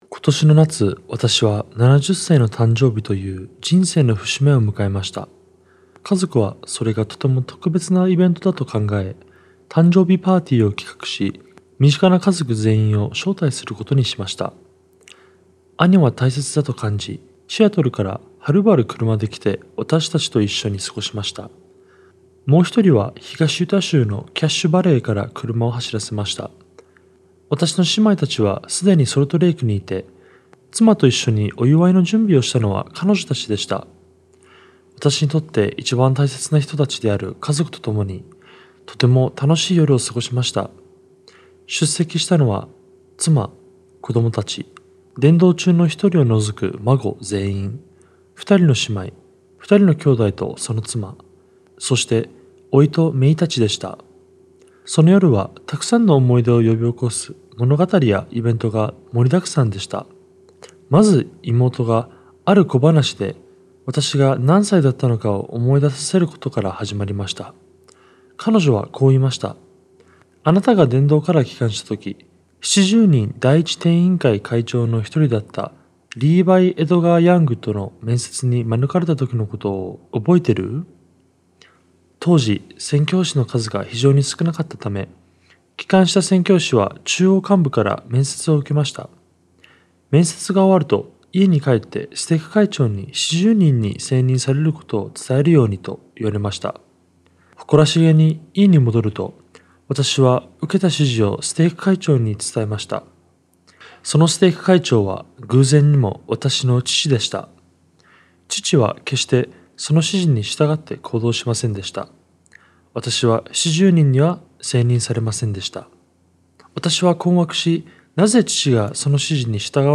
Audio recording of 思い出を作る by L. トム・ペリー